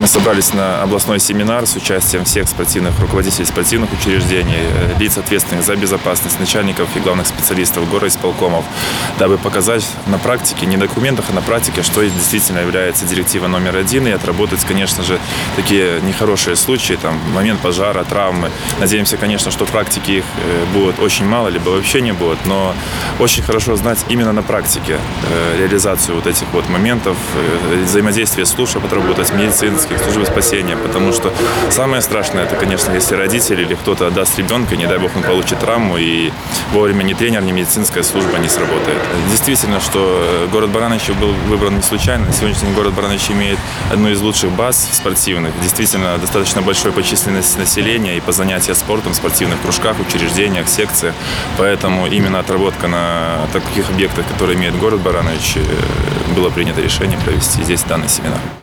Начальник управления спорта и туризма облисполкома Юрий Хомич рассказал для чего так важно проводить такие мероприятия не на бумаге, а на практике. И почему именно наш город стал принимающей стороной такого масштабного события.